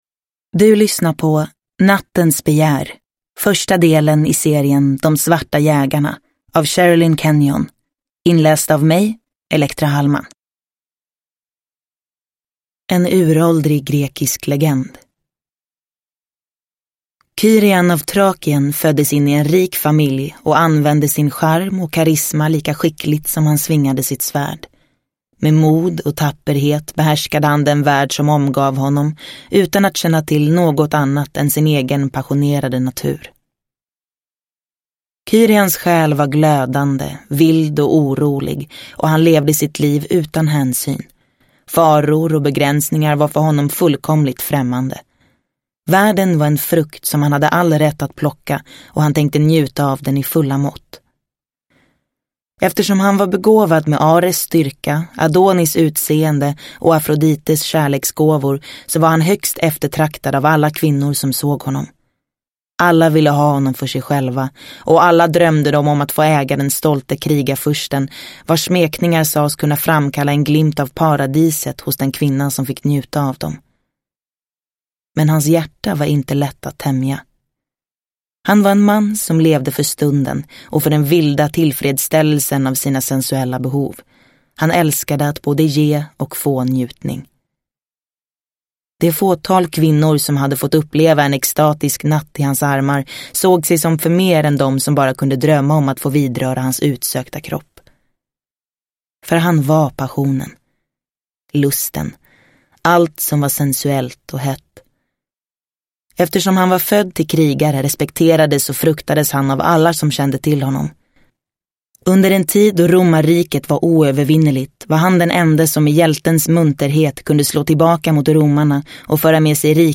Nattens begär – Ljudbok – Laddas ner